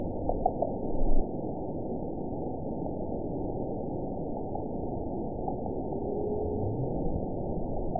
event 922206 date 12/28/24 time 07:03:46 GMT (11 months, 1 week ago) score 9.25 location TSS-AB03 detected by nrw target species NRW annotations +NRW Spectrogram: Frequency (kHz) vs. Time (s) audio not available .wav